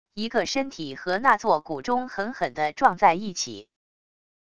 一个身体和那座古钟狠狠地撞在一起wav音频